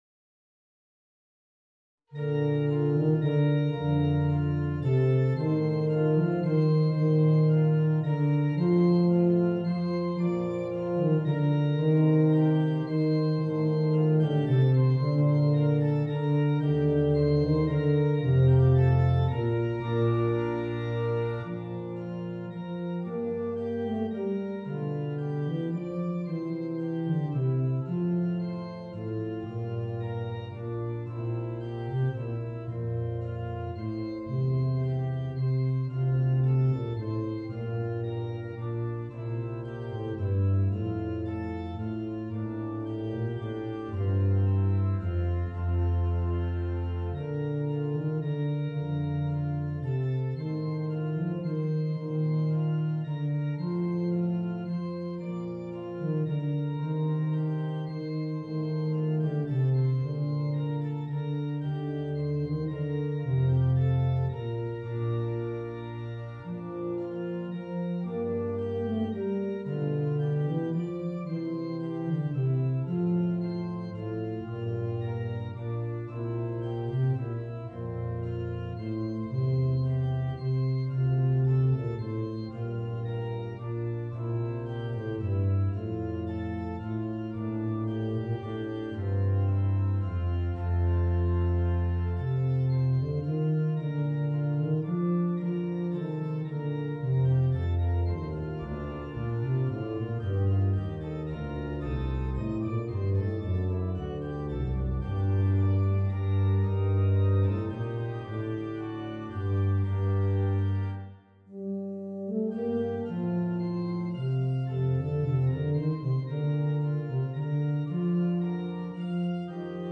Eb Bass and Organ